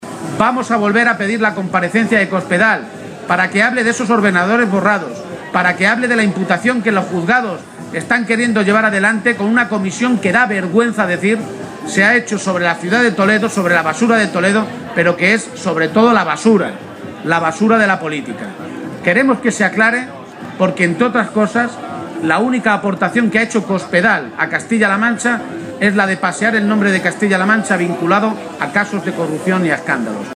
García-Page se pronunciaba de esta manera este mediodía, en una comparecencia ante los medios de comunicación durante su visita a la Feria de Talavera.